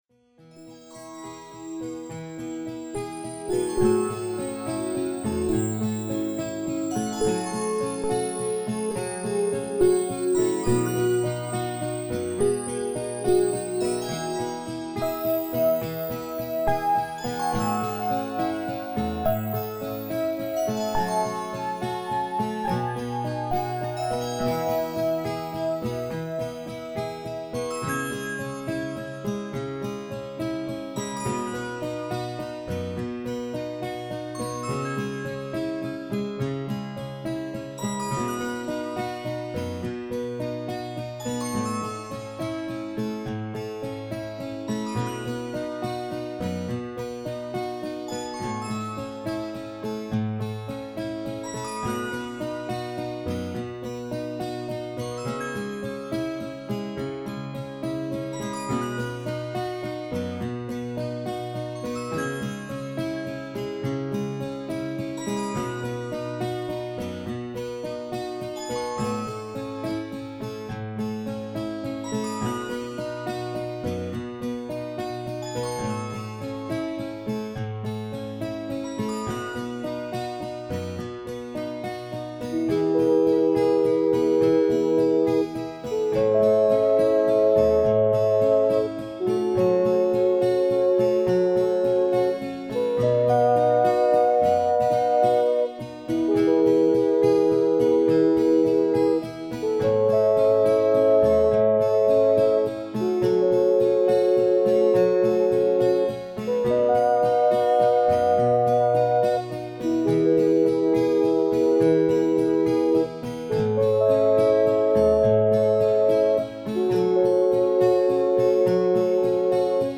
Фонограмма: